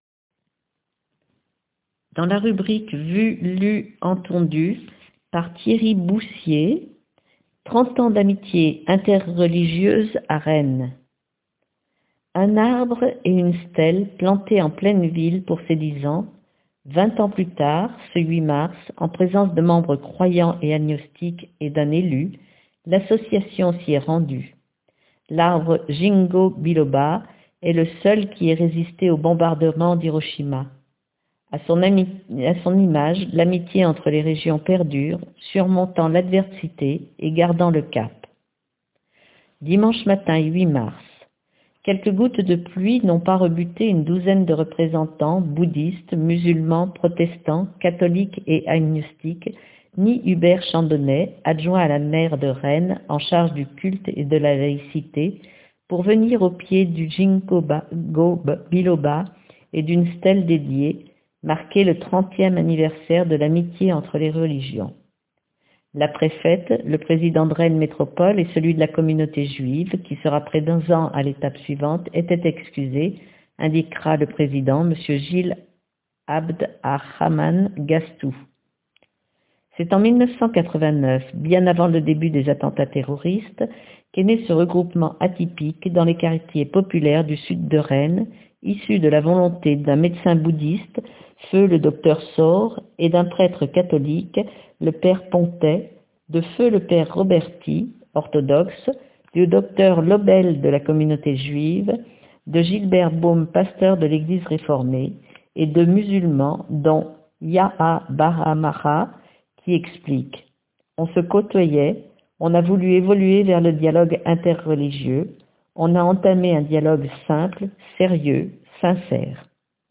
Une rencontre sobre autour de symboles au Contour de la motte : un arbre qui n'a pas encore de feuilles et une stèle de granite. (photo : Th B) 30 ans.mp3 (2.66 Mo) Dimanche matin 8 mars.